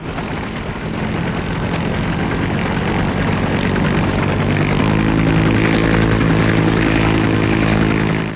دانلود صدای طیاره 6 از ساعد نیوز با لینک مستقیم و کیفیت بالا
جلوه های صوتی